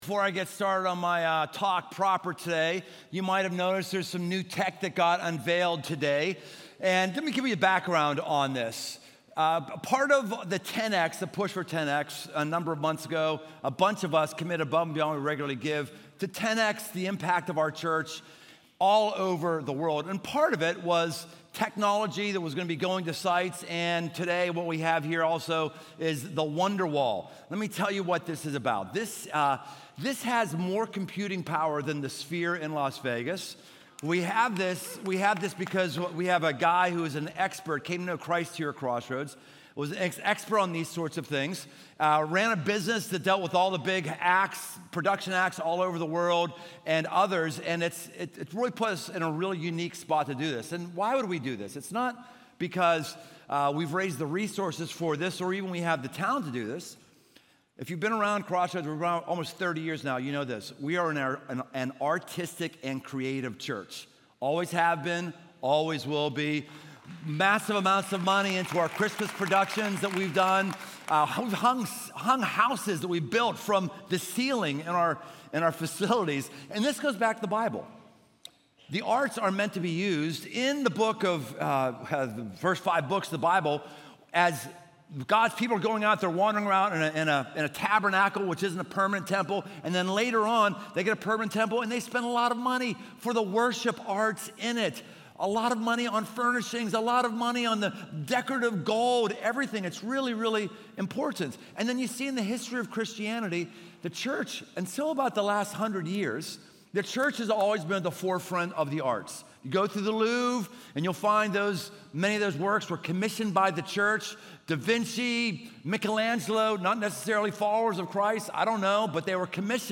Recorded live at Crossroads Church in Cincinnati, Ohio.